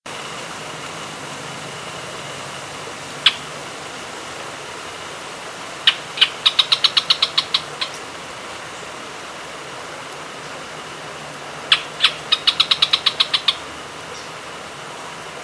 Canebrake Groundcreeper (Clibanornis dendrocolaptoides)
Life Stage: Adult
Location or protected area: Parque Provincial Araucaria
Condition: Wild
Certainty: Recorded vocal